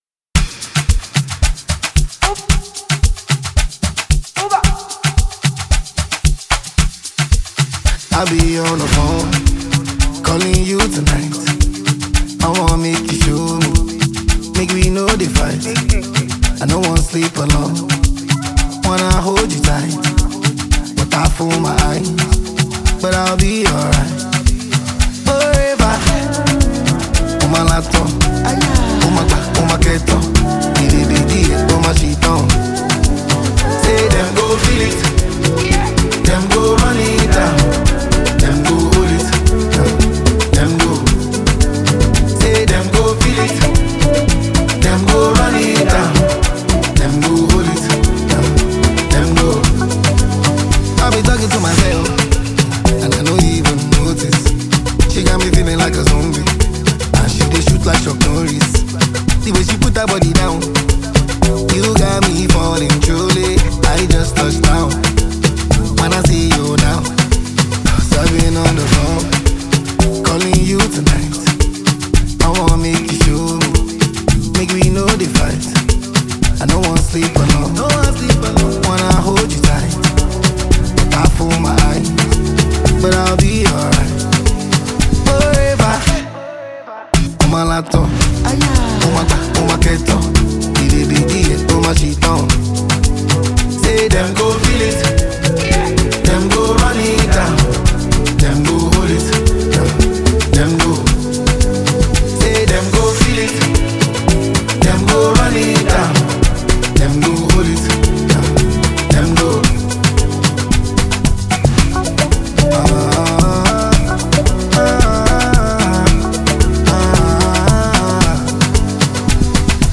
The album mixes Afrobeats, amapiano, and highlife sounds.